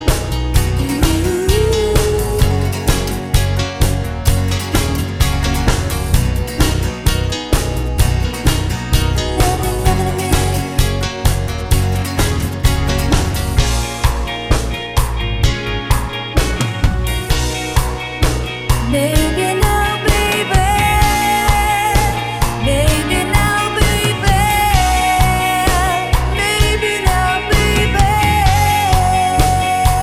One Semitone Down Pop (1980s) 4:14 Buy £1.50